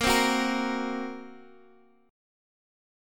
Bbsus2#5 chord